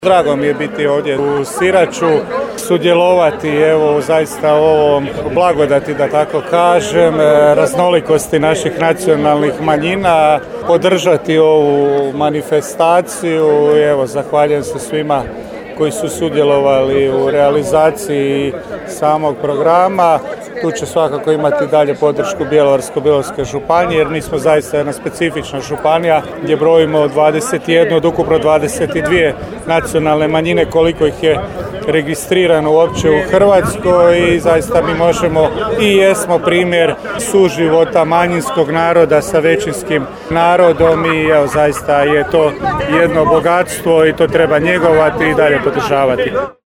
U subotu je upriličena 9. Smotra manjinskog stvaralaštva.
U ime županije koja zna istaknuti svoje posebnosti, župan Marko Marušić iz Sirača poručio je: